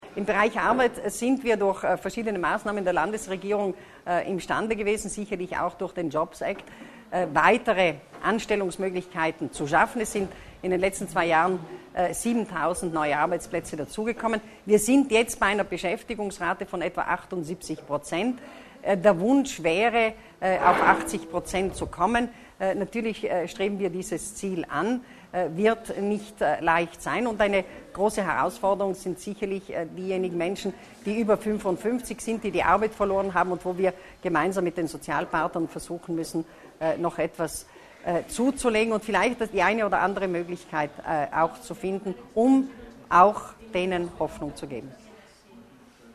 Landesrätin Stocker erläutert neue Strategien für den Arbeitsmarkt
Bürger und Unternehmen entlasten: Unter diesem Titel stellte Landesrätin Martha Stocker den dritten Themenbereich ihrer Halbzeitpressekonferenz (24. Juni.).